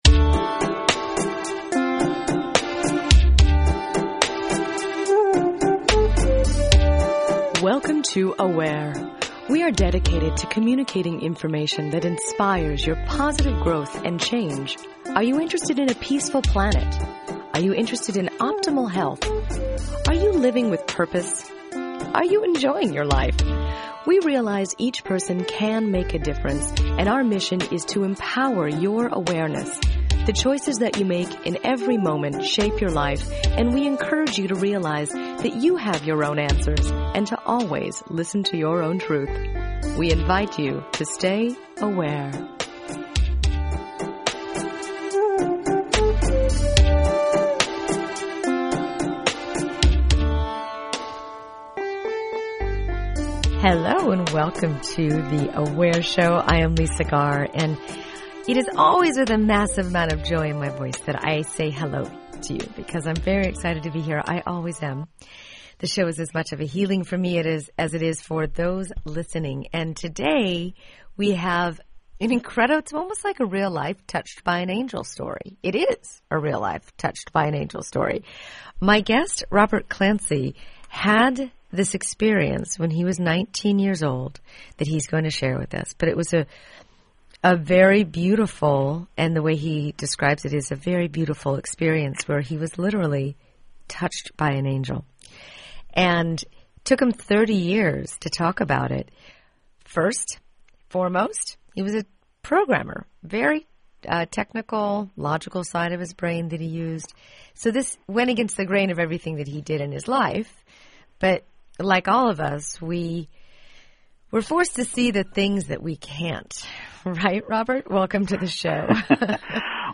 Find out more during this special fund drive show.